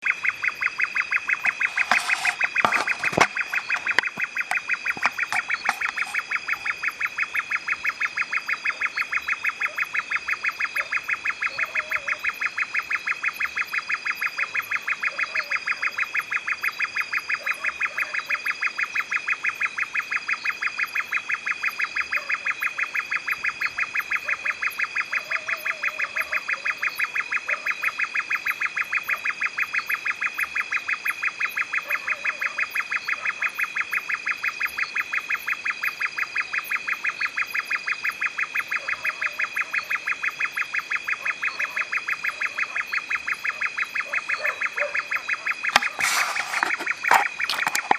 Slender-tailed Nightjar Song
Enjoy the song of a Slender-tailed Nightjar that I recorded in Lake Baringo.
slender-tailed-nightjar.mp3